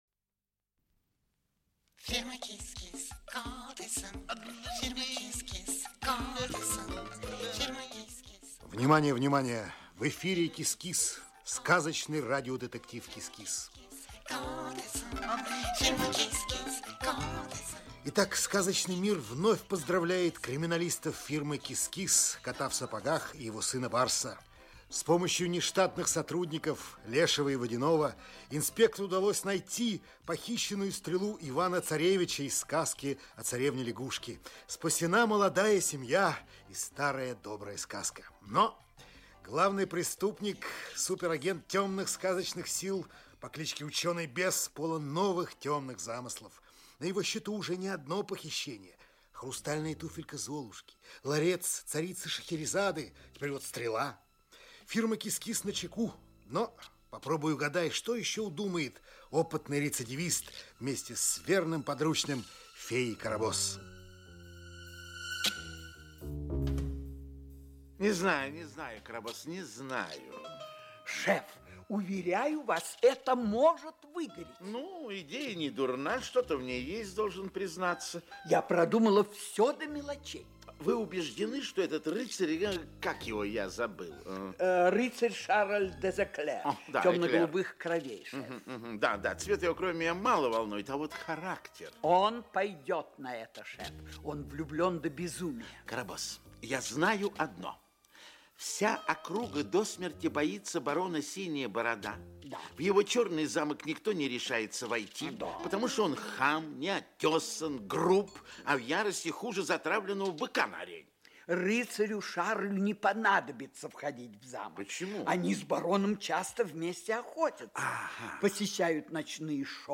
Аудиокнига КИС-КИС. Дело № 6. "Тайна Черного Замка". Часть 1 | Библиотека аудиокниг